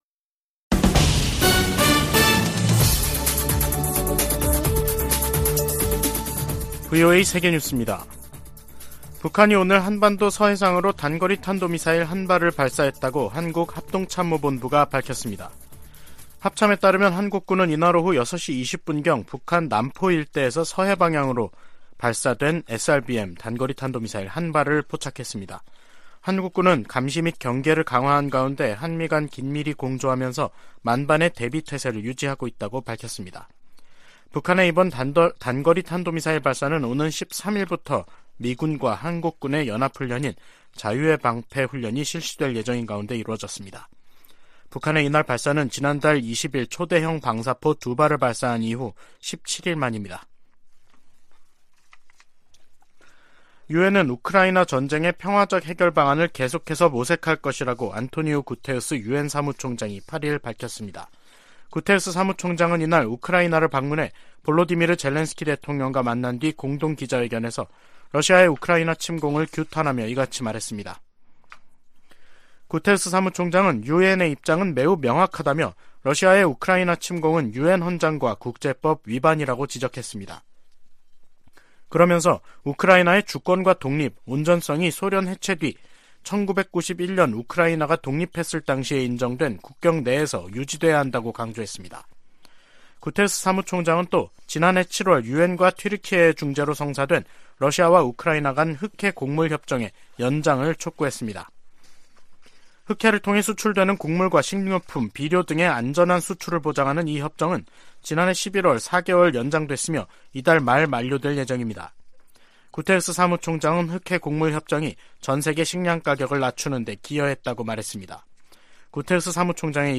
VOA 한국어 간판 뉴스 프로그램 '뉴스 투데이', 2023년 3월 9일 3부 방송입니다. 윤석열 한국 대통령이 오는 16일 일본을 방문해 기시다 후미오 총리와 정상회담을 갖는다고 한국 대통령실이 밝혔습니다. 미 국무부는 미한일 3자 확장억제협의체 창설론에 대한 입장을 묻는 질문에 두 동맹국과의 공약이 철통같다고 밝혔습니다. 권영세 한국 통일부 장관은 미래에 기초한 정책을 북한 지도부에 촉구했습니다.